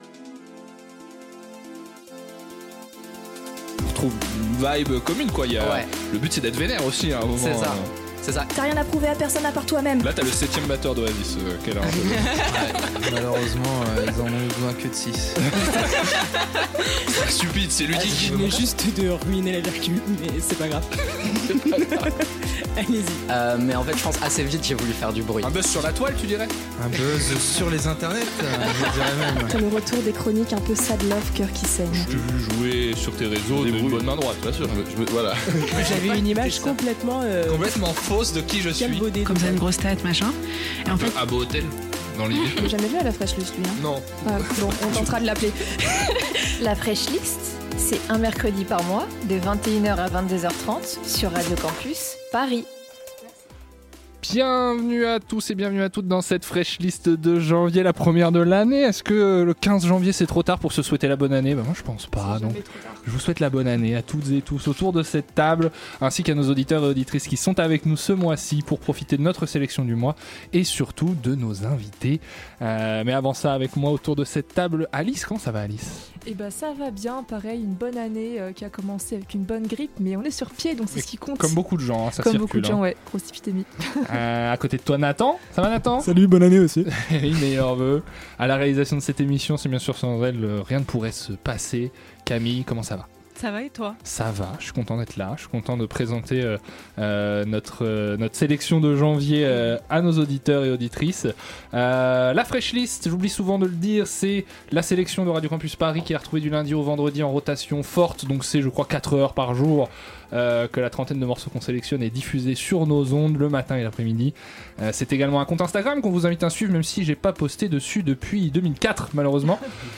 La Fraîche Liste est de retour pour une nouvelle saison en direct !
Ce mois-ci, c'est le groupe The Odds qui nous fait le plaisir d'être avec nous en studio, pour une heure d'échanges sur le rock en France, le challenge de composer et tourner à 4, et l'importance de parvenir à retranscrire l'énergie live sur les morceaux enregistrés.